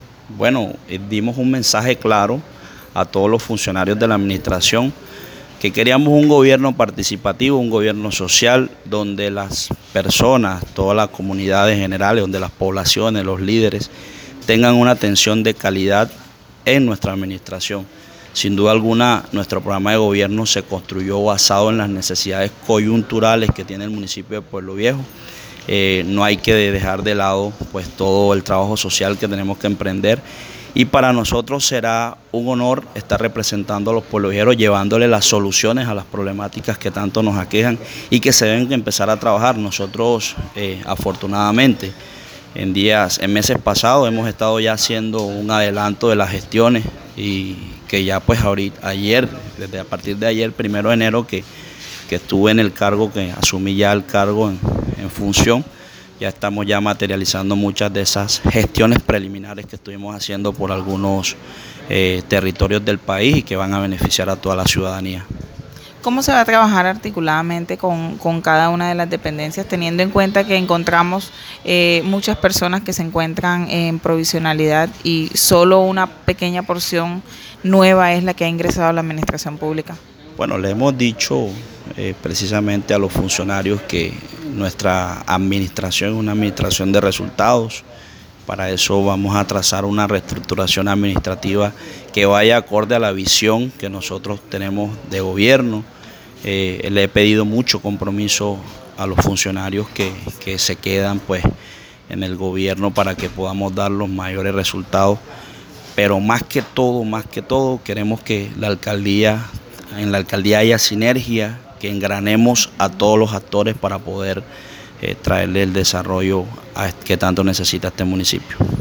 El alcalde de Puebloviejo, Fabián David Obispo Borja, realizó su primer consejo de gobierno, y con mucha pena, tuvo que hacerlo con todos de pie, porque en el despacho no encontró sillas ni mesas para poder realizar esta sesión tan importante. Sin embargo, esto no impidió que trazara las líneas estratégicas con que quiere dirigir el Municipio.
Fabian-Obispo-Consejo-de-Gobierno.m4a